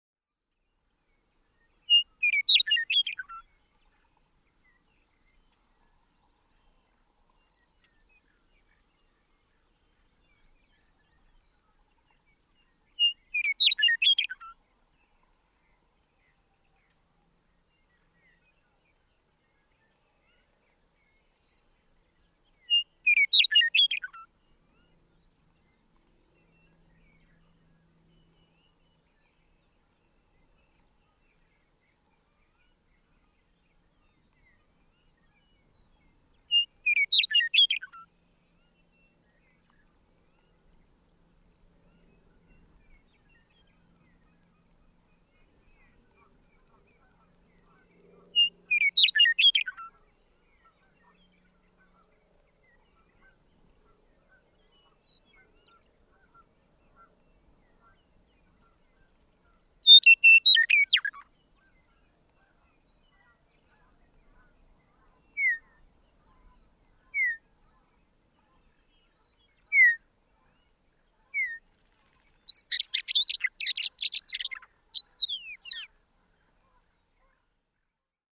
Western meadowlark
After six perched songs of two different types, this male gives a few low whistles (1:05 to 1:12), then launches into flight, delivering an ecstatic, hurried twitter of a song.
Arapaho National Wildlife Refuge, Walden, Colorado.
551_Western_Meadowlark.mp3